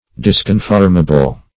Search Result for " disconformable" : The Collaborative International Dictionary of English v.0.48: Disconformable \Dis`con*form"a*ble\, a. Not conformable.